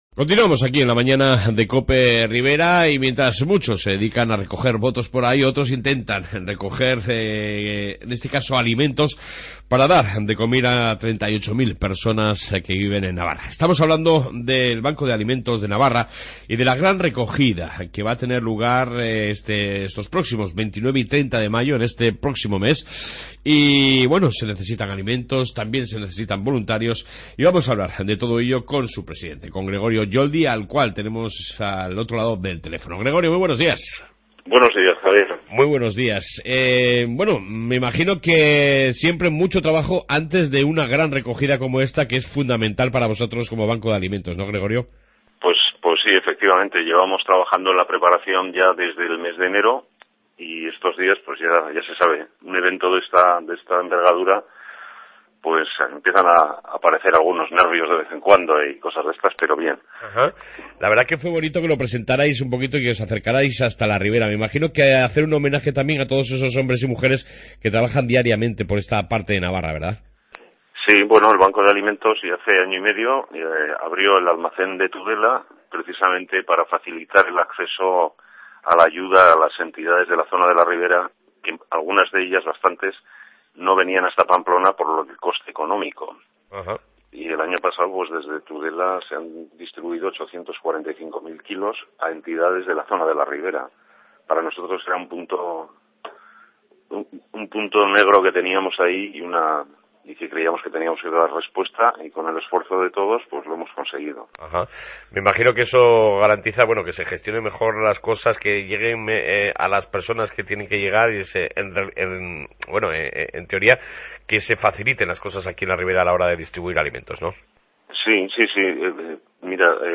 AUDIO: Entrevista sobre la Gran Recogida de alimentos en la Ribera